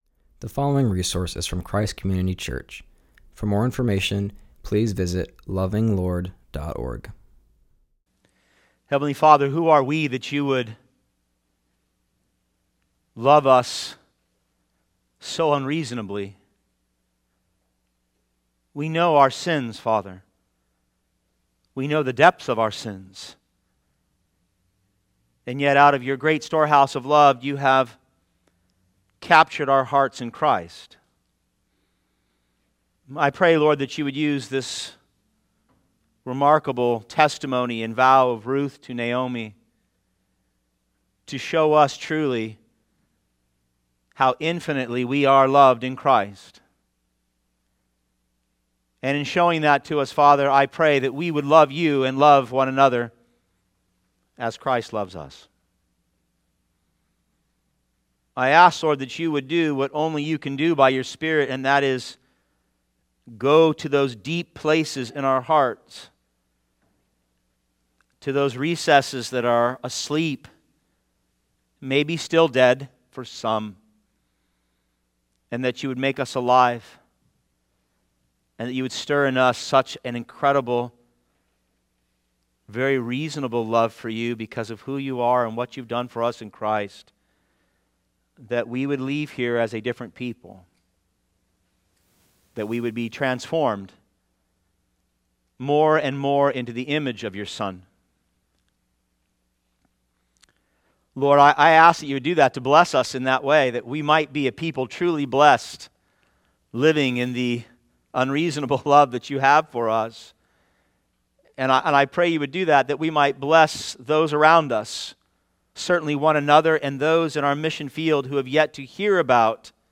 continues our series and preaches on Ruth 1:7-18.